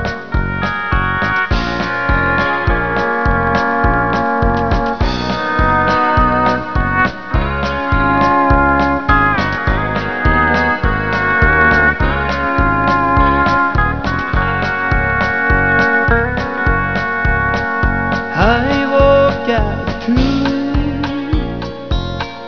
Her er lydprøve på mellomspill / melodien